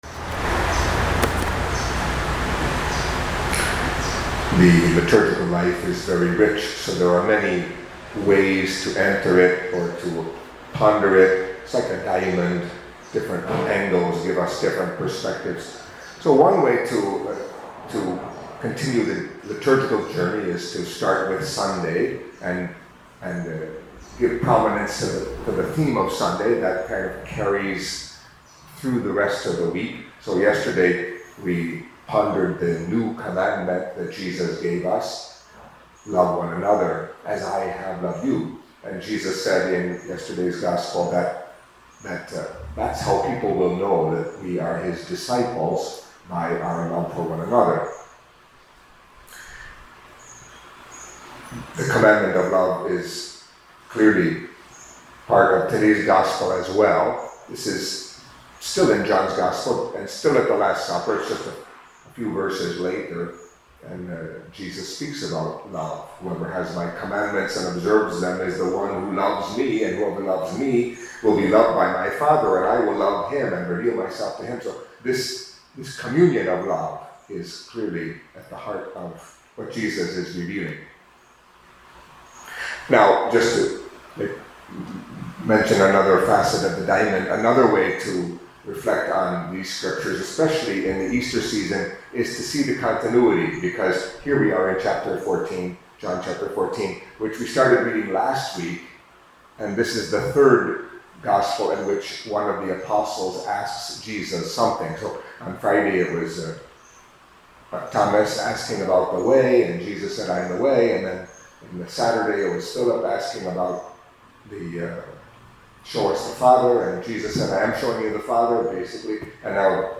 Catholic Mass homily for Monday of the Fifth Week of Easter